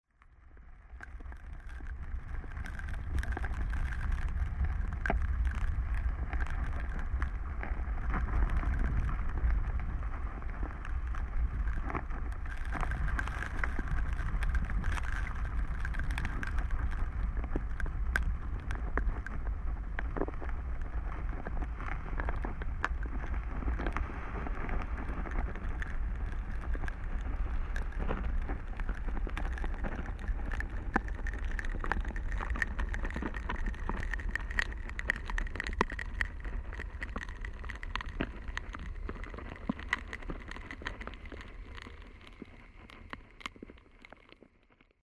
a friend once commented how many of my recordings are from a perspective up close. for me it is this perspective that I return to and find is important, an audible view of a situation – in this case a continuous event – that is not readily audible, or at least is greatly enhanced through amplification from the contact mic surface. for this release movement of water in two forms become the basis of exploration. one active one passive. each revealing some inherent noise, compression, hum and tension of existence.
Field Recording Series by Gruenrekorder
The source sounds are streams of water, rain, crackles that seem stones moved by the force of the water, wind blowing and birds.